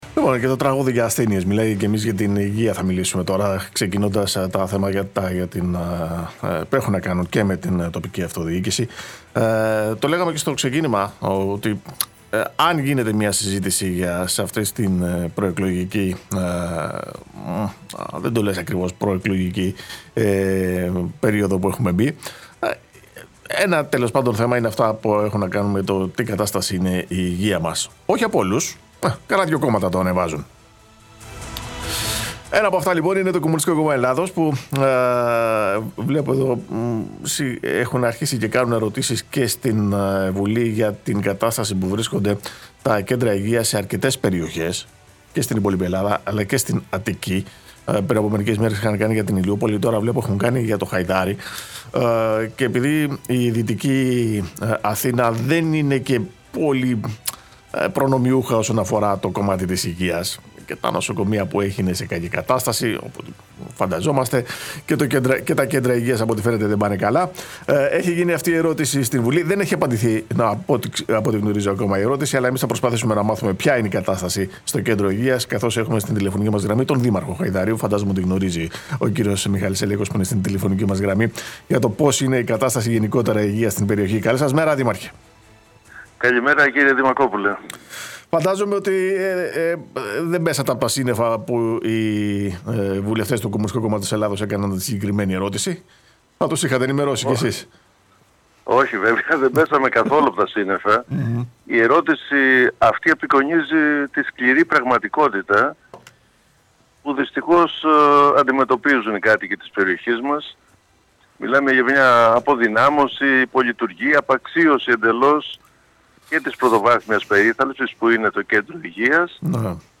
Ο Δήμαρχος Χαϊδαρίου Μιχάλης Σελέκος μίλησε στον Επικοινωνία FM